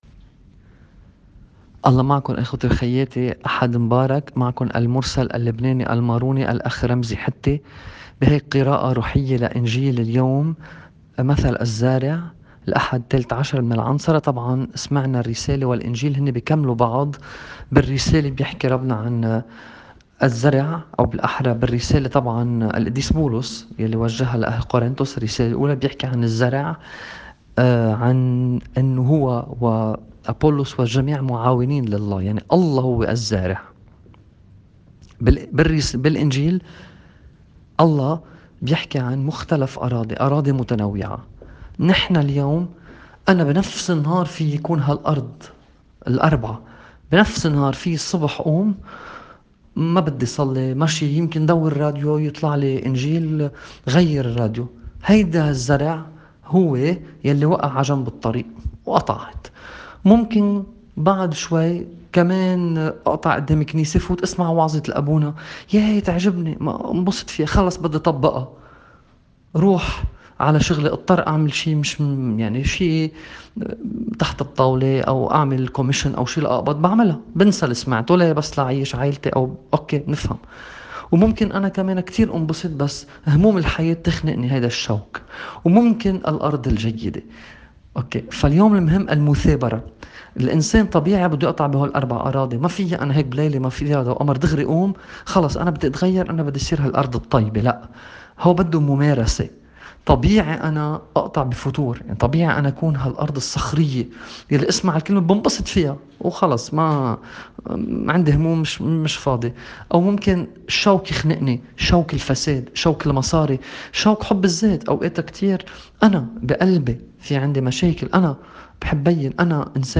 تعليق على إنجيل اليوم